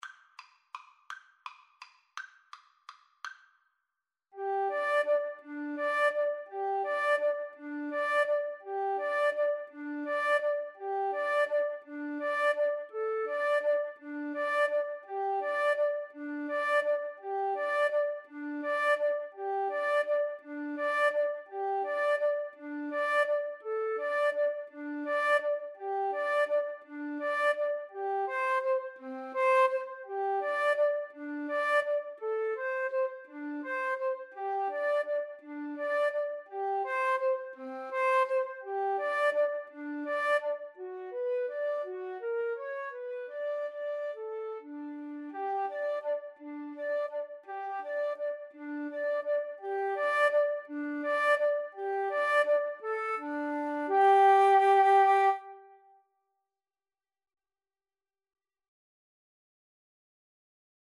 Free Sheet music for Flute Duet
3/4 (View more 3/4 Music)
G major (Sounding Pitch) (View more G major Music for Flute Duet )
Tempo di valse =168
Classical (View more Classical Flute Duet Music)